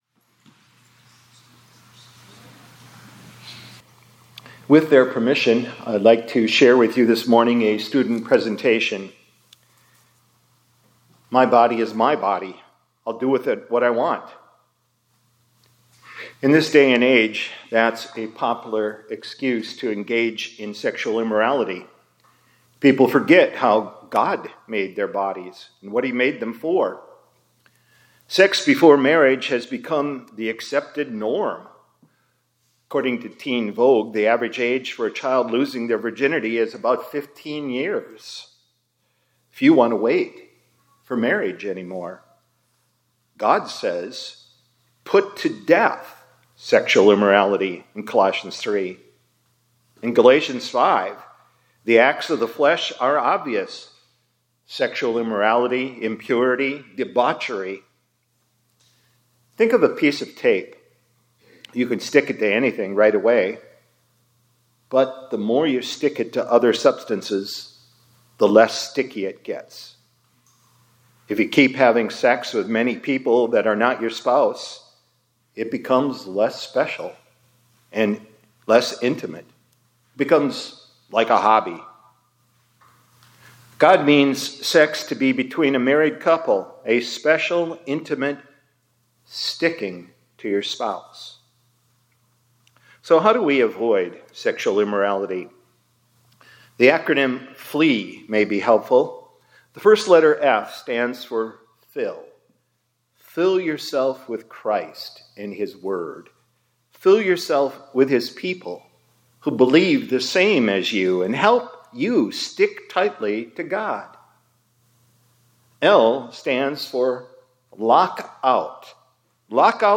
2026 Chapels -